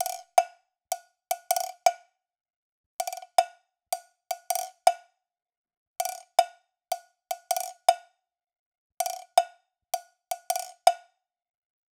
Index of /m8-backup/M8/Samples/Loops/Perc Construction Loops/Cowbell
SD_SEISMIC_cowbell_loop_02_80.wav